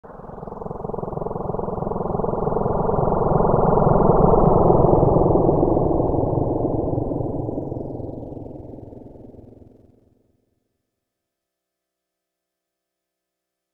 Copter.wav